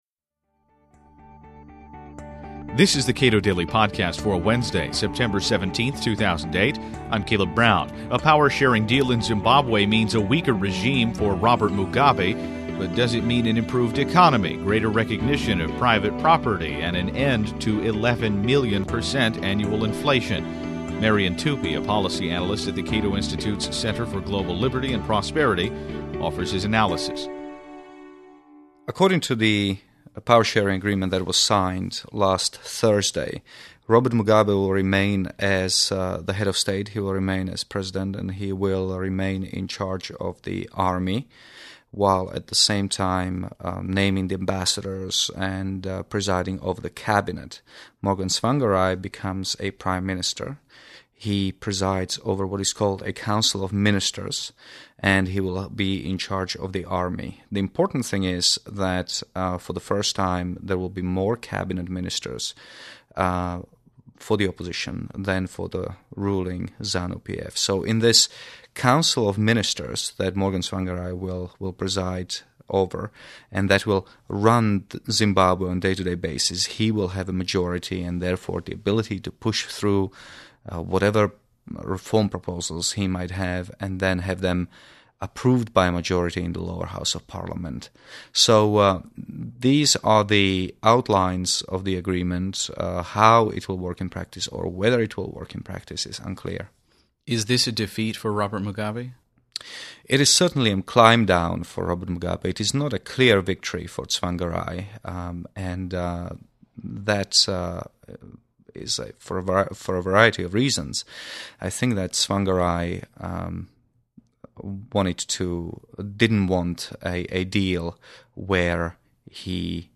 The Cato Daily Podcast allows experts and scholars affiliated with the Cato Institute to comment on relevant news in a conversational, informal manner.